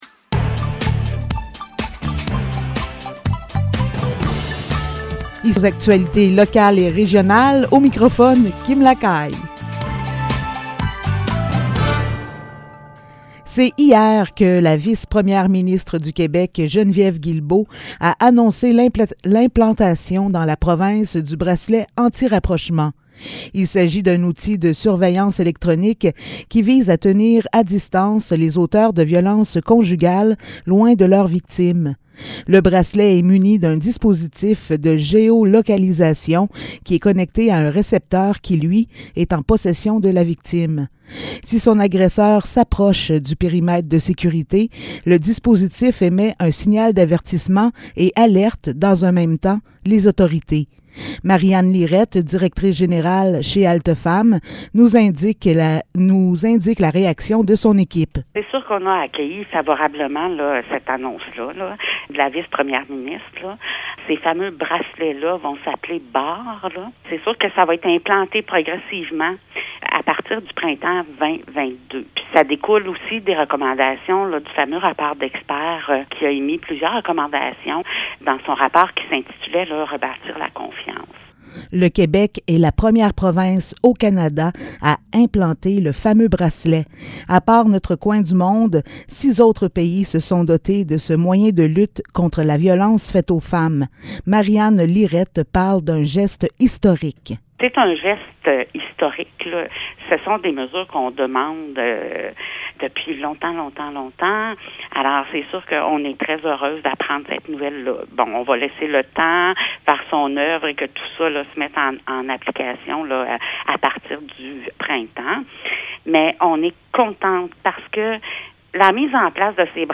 Nouvelles locales - 2 décembre 2021 - 15 h